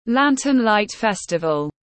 Lantern Light Festival /ˈlæn.tɚn laɪt ˈfes.tə.vəl/